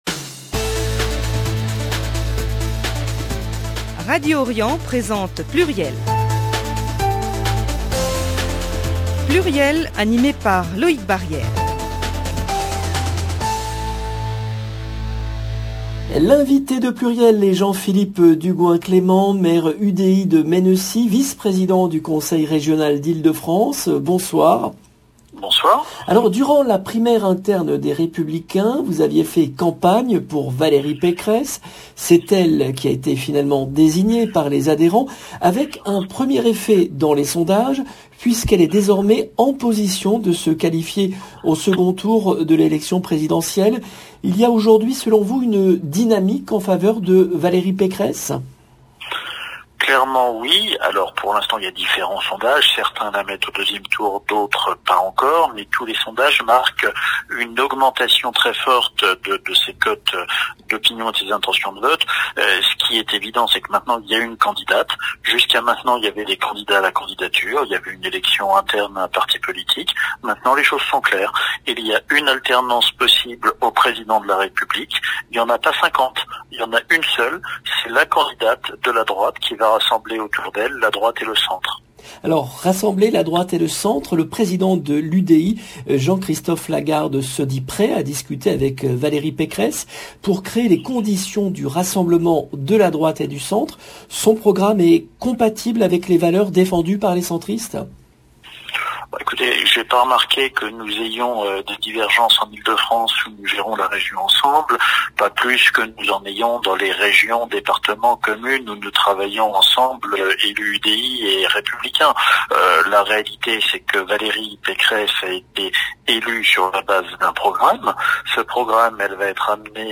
Jean-Philippe Dugoint-Clément, vice-président du Conseil Régional d'Ile-de-France
L’invité de PLURIEL est Jean-Philippe Dugoint-Clément , maire UDI de Mennecy, Vice-Président du Conseil régional d’Ile-de-France, soutien de Valérie Pécresse pour l’élection présidentielle